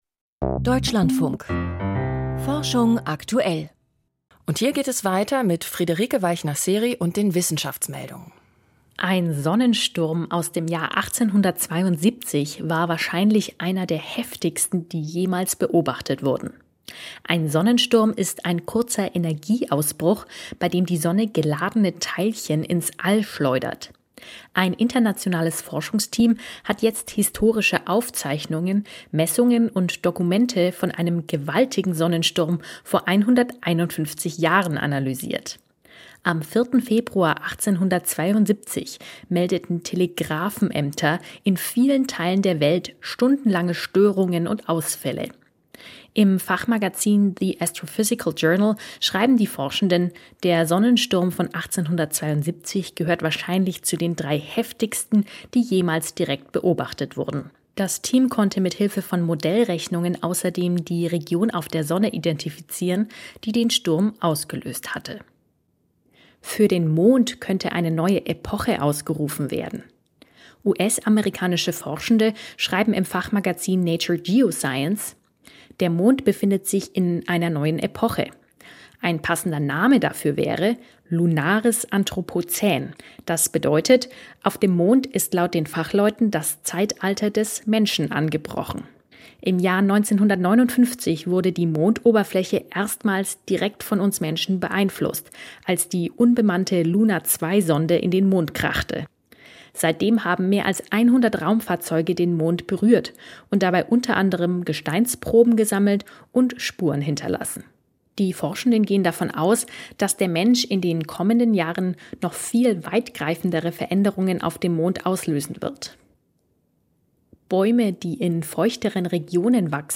Täglich das Wichtigste aus Naturwissenschaft, Medizin und Technik. Berichte, Reportagen und Interviews aus der Welt der Wissenschaft.